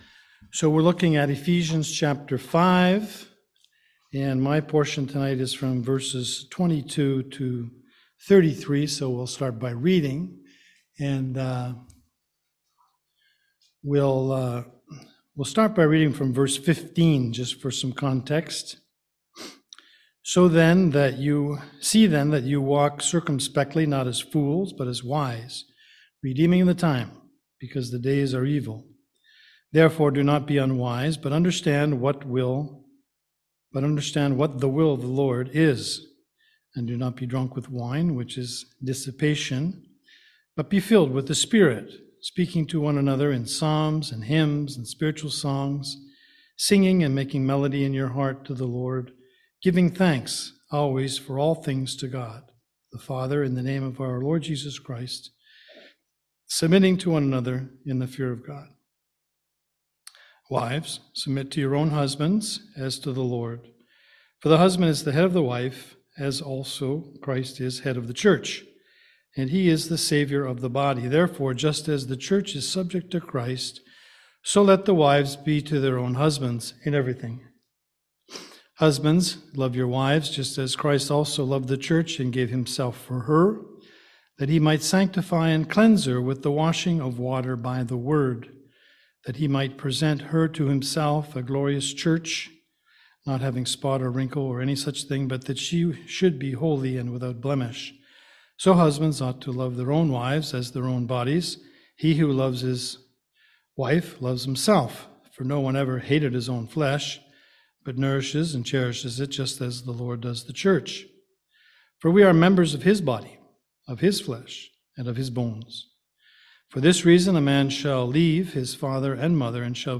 Passage: Ephesians 5:22-33 Service Type: Seminar